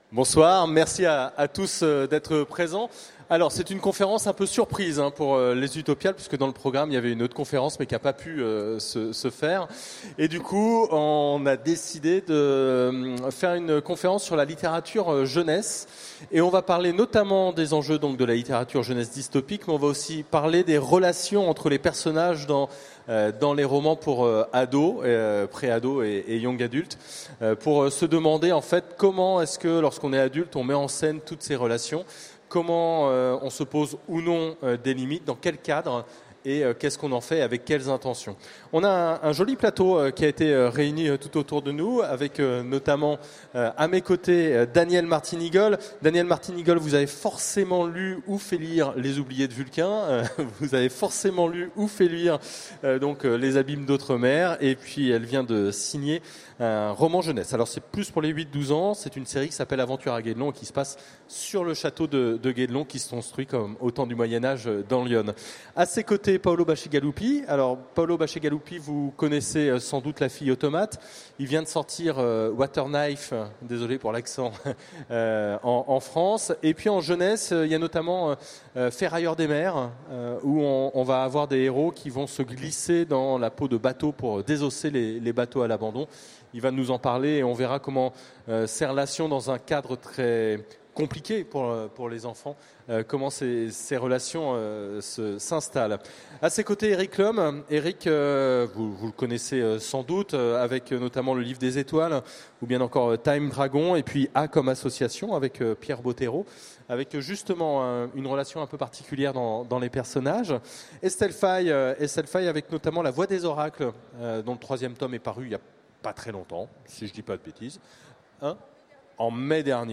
Utopiales 2016 : Conférence Enjeux de la littérature jeunesse dystopique